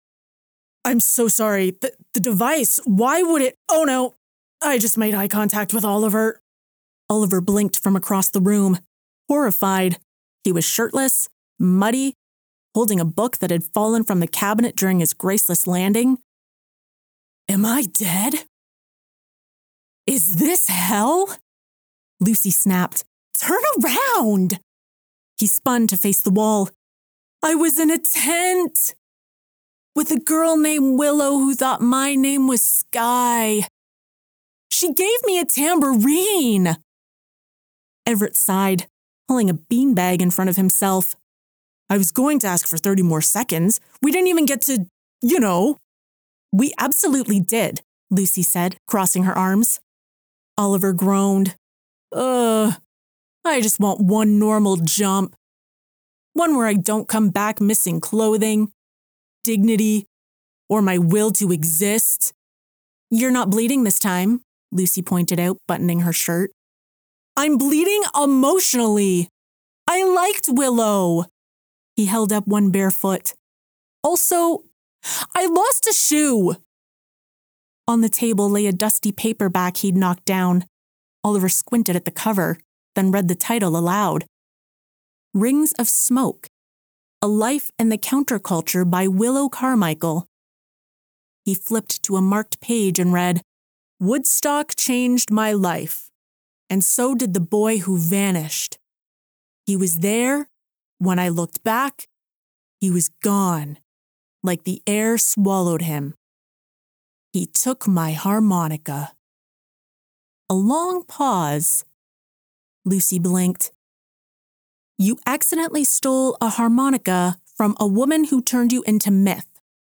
4. Science Fiction, comedic timing, female voicing male and female characters (From "Five Seconds Too Late")
I have a professional sound treated recording studio with industry standard microphones, equipment, and recording / audio editing software.